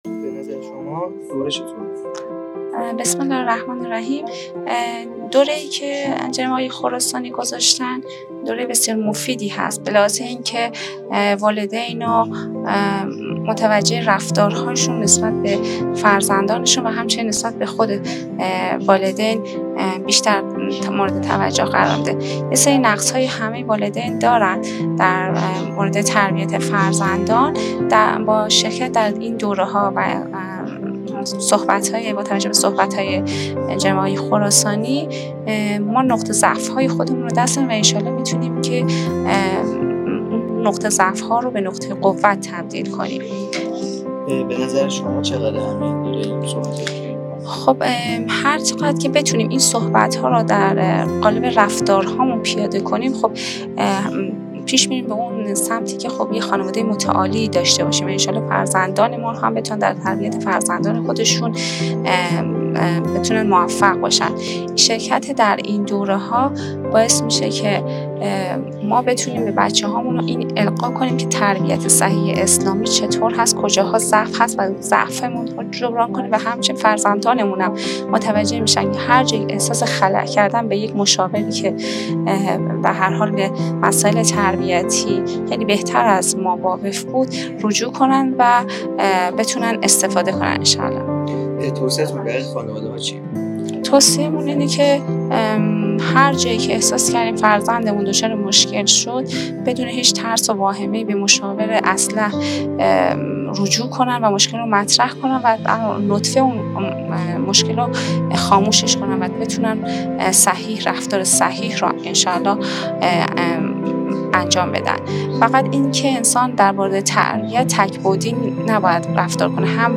مصاحبه با خانواده هایی که در دوره واکسینه تربیتی فرزند شرکت کرده اند